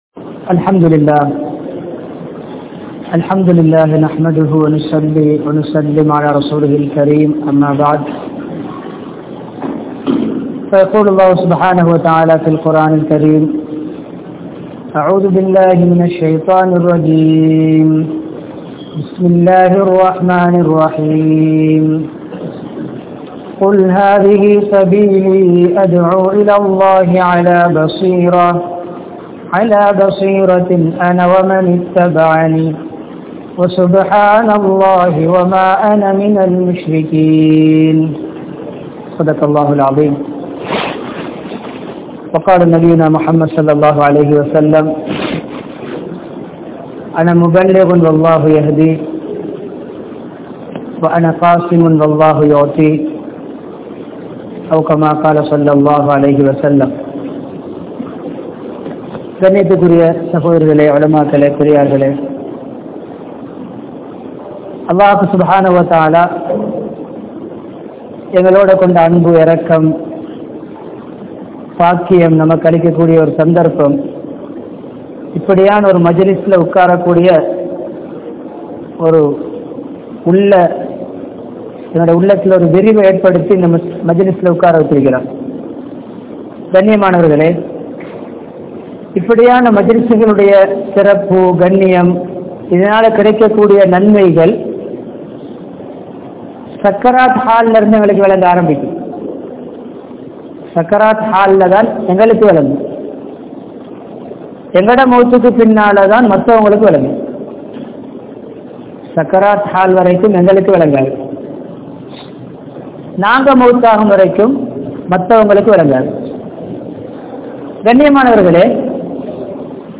Maqaami Amalhalin Mukkiyaththuvam (மகாமி அமல்களின் முக்கியத்துவம்) | Audio Bayans | All Ceylon Muslim Youth Community | Addalaichenai